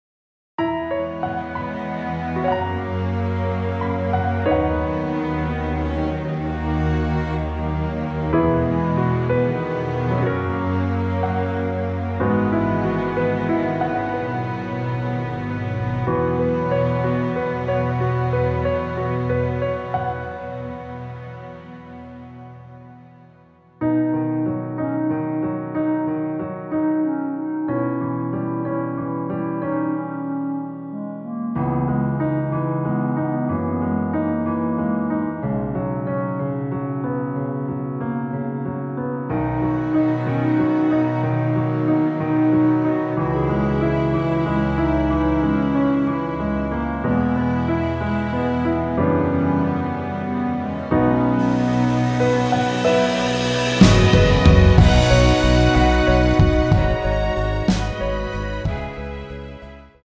원곡보다 짧은 MR입니다.(아래 재생시간 확인)
원키에서(-2)내린 (짧은편곡)멜로디 포함된 MR입니다.
앞부분30초, 뒷부분30초씩 편집해서 올려 드리고 있습니다.
중간에 음이 끈어지고 다시 나오는 이유는